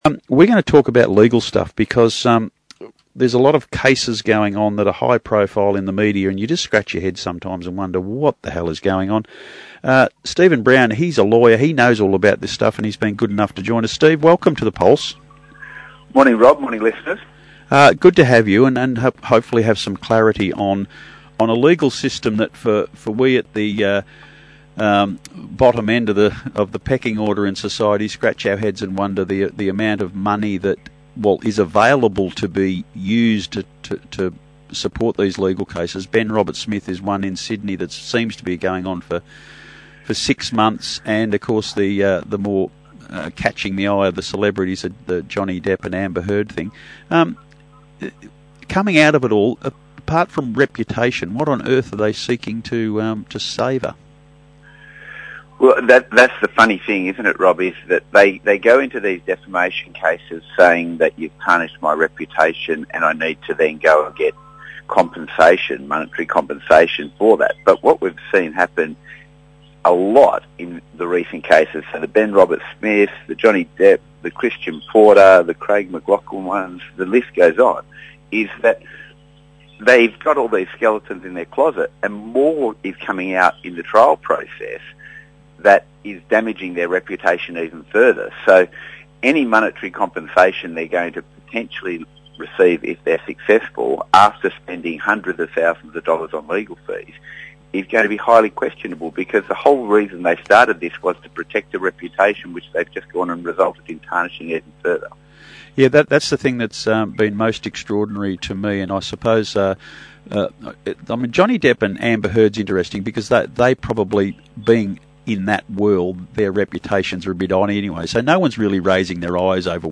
Radio interview on Pulse FM: Defamation cases and the effects of the reputation of the litigants - Johnny Depp, Ben Roberts-Smith, Christian Porter, etc.